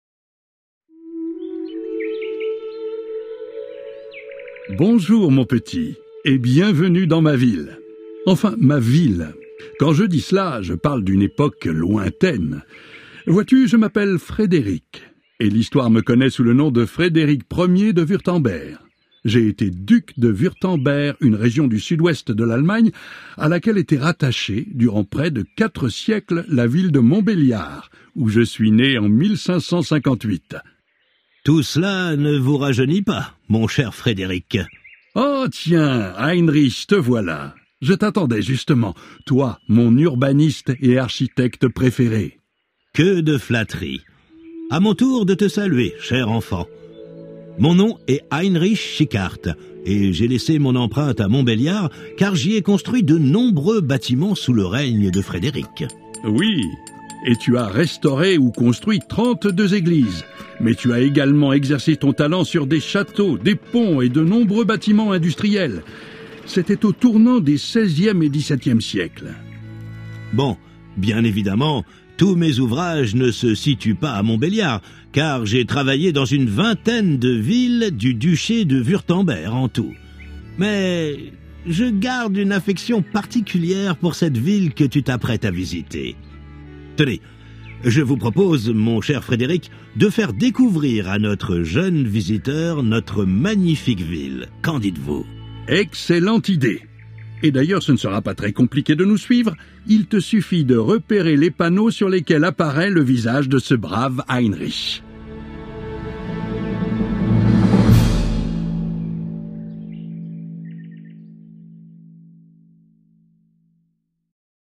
Il s’agit d’une discussion entre Frédéric 1er, duc de Wurtemberg et son architecte, Heinrich Schickhardt.